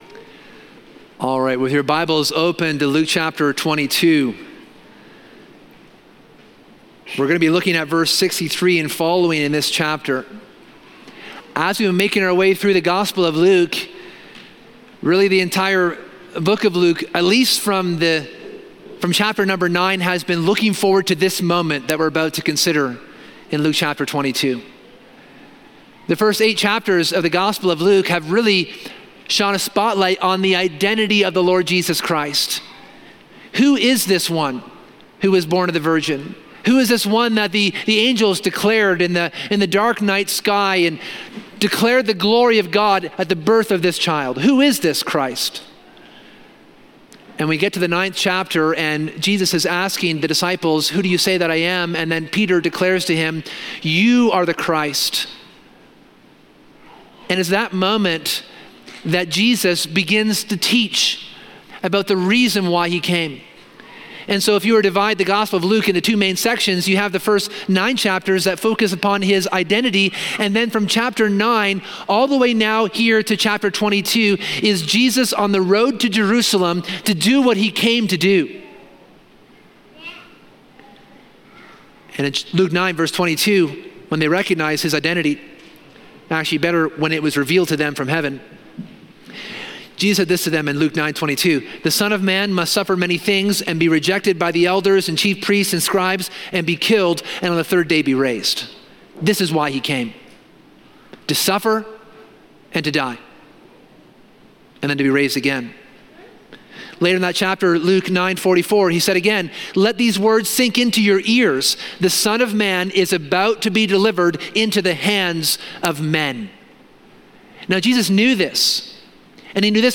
This sermon explores the arrest, trial, and conviction of Jesus Christ, showing the profound injustice he endured and his unwavering faithfulness.